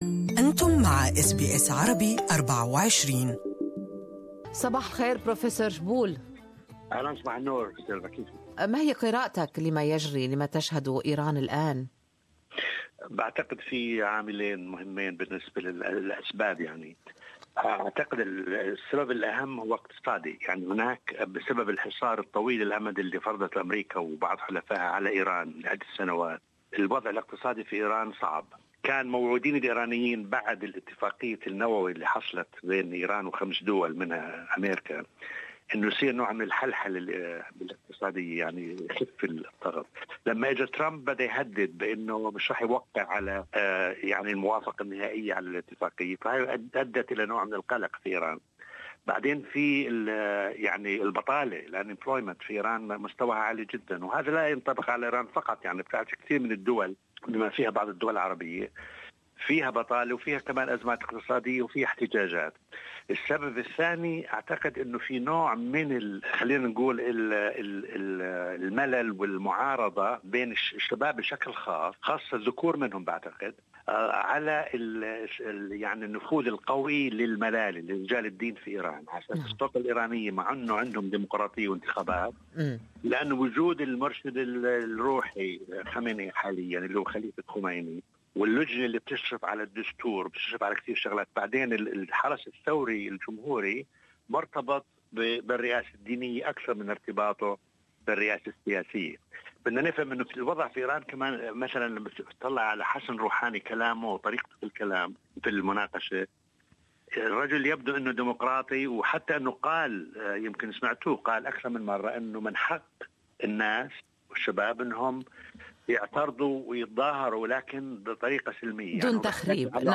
Good Morning Australia interviewed